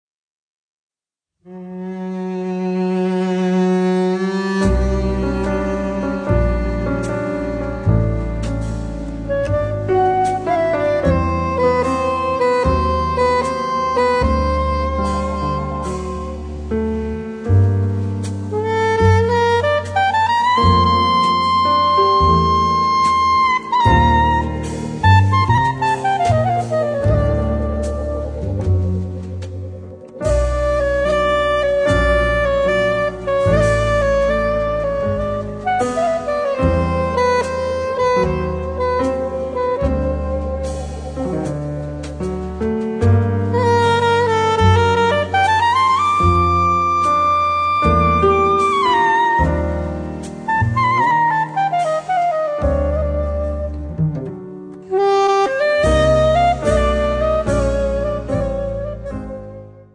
piano
contrabbasso
batteria
bandoneon, electronics